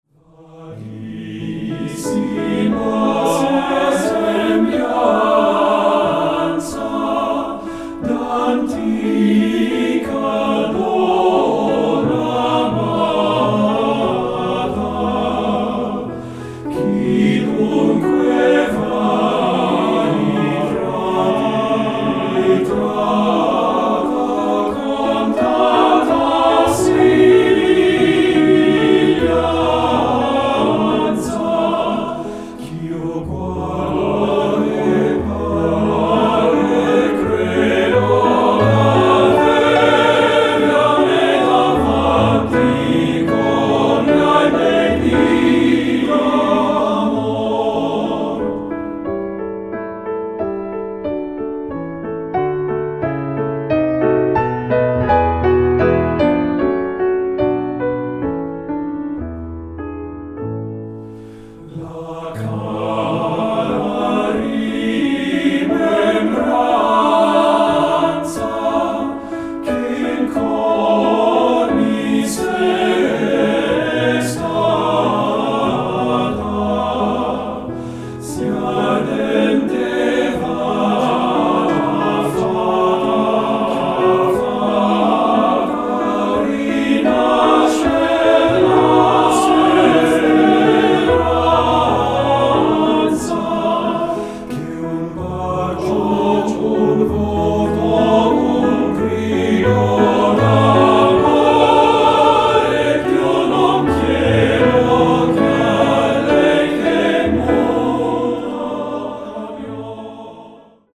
Choral Male Chorus
Voicing